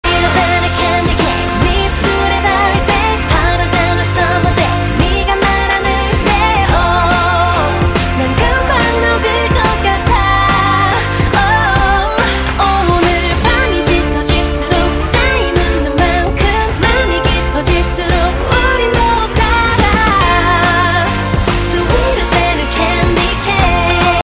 Thể loại nhạc chuông: Nhạc hàn quốc